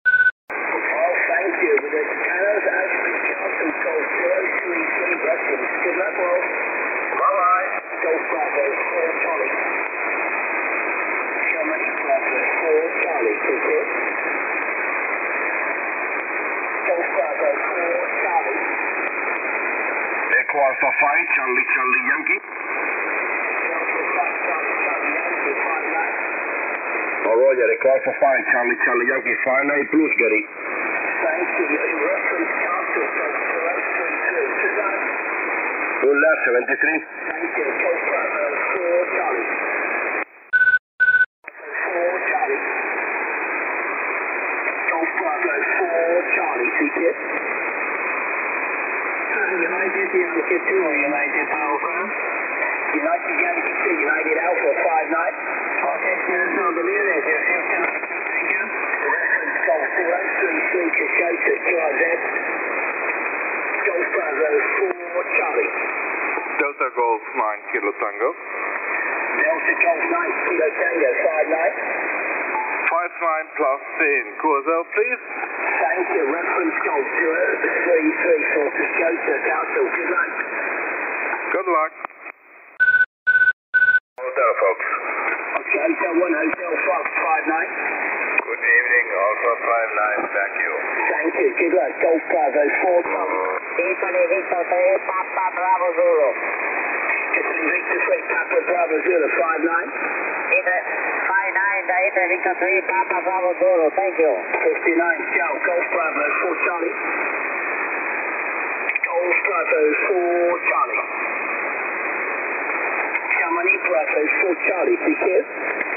I have marked the start of the inverted L with one beep, the dipole with two beeps, and the Steppir with three.
Firstly he is quite faint on the inverted L, primarily because the inverted L is vertically polarized, and will favour a longer distance. There is not much to choose between the trapped dipole and the rotary dipole of the Steppir which is broadside to the station.